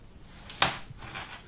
描述：在这里，我试图收集我在家里发现的所有扣件。其中大部分在夹克衫上，一个手提包里有啷个球，还有一些雪裤。
Tag: 点击 服装和-配件 扣紧固件